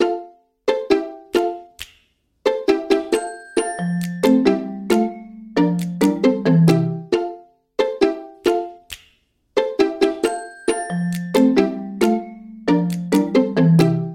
• Качество: 128, Stereo
Стандартный рингтон